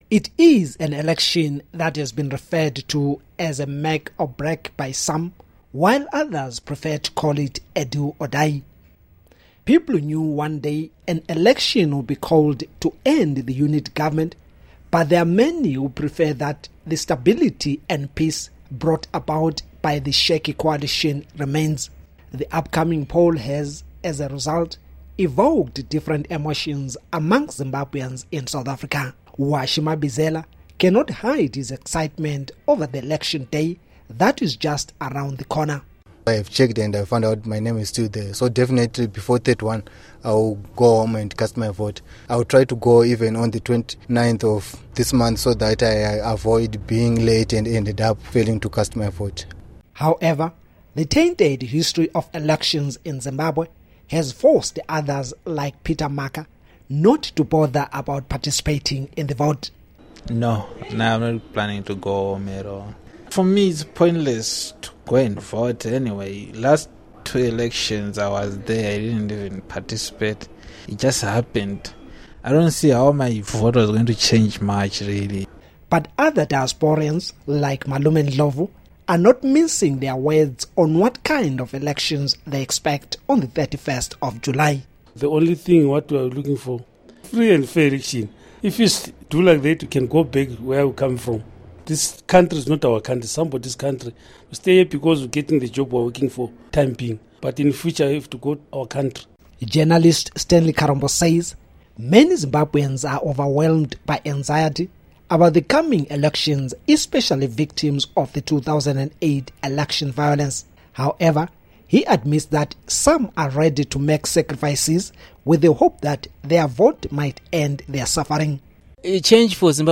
Zimbabweans Living in South Africa Speak Out on Elections